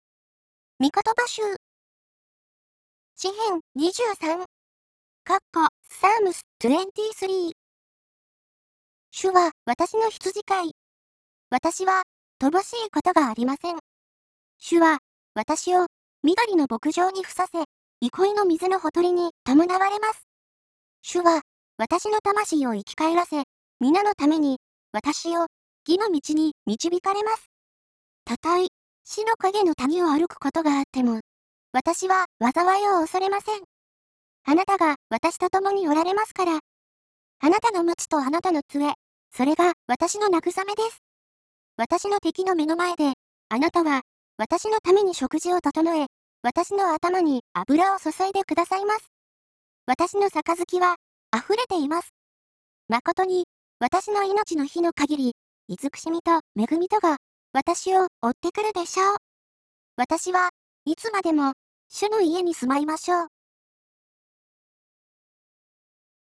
入力あるいはコピペしたテキストを読み上げてくれるソフトなのですが、 「東北ずん子」というキャラクター(声優)の合成音声によって読み上げてくれます。
やっぱり声が若干萌えっぽいので、低めに抑え目に調整しました。
東北ずん子読み上げ「詩篇、23」ダウンロード
word06_psalms_23.wav